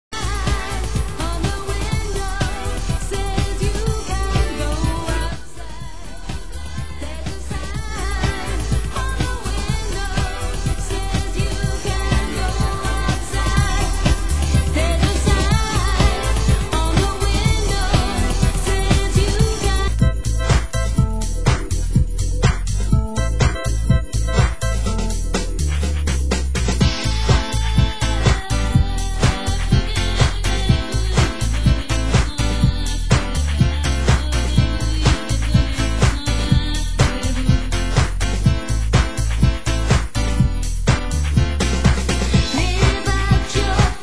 Format: Vinyl 12 Inch
Genre: UK House